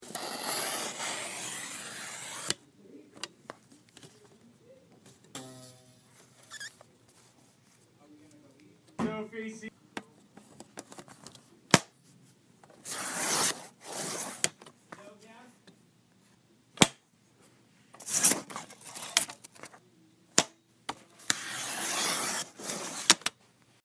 Field Recording # 6
This is the sound of my paper cutter as I slice my resumes to fit my headshots.
I repeated the motion a few times to recreate the sound. You can also here the plastic cover snapping to hold the paper in place, the blade as it cuts, and in the beginning, the vibration of the blade as I accidentally knocked it, kind of a boing-oing-oing.
Paper-cutter.mp3